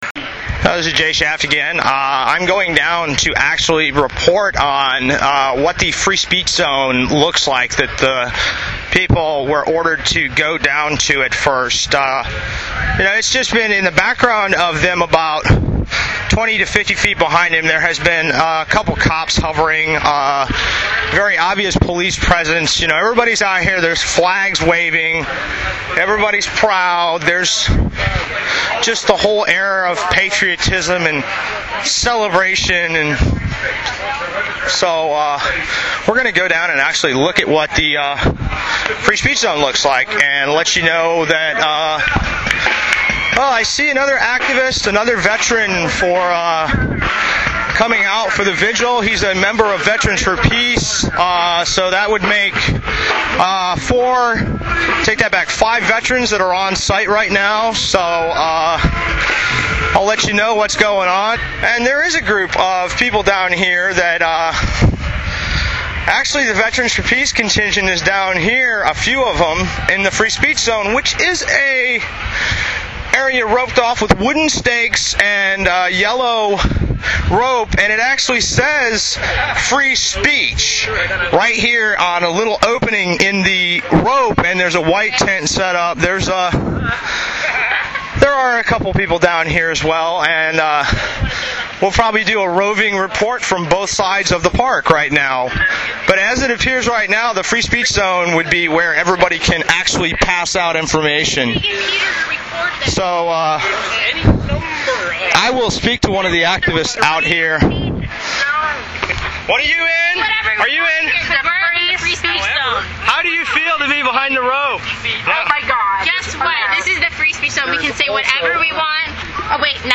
Report from the actual "Free Speech Zone" and an interview with a member of VFP. Follow up report as veterans took down the yellow ropes and stakes of the so called "Free Speech Zone".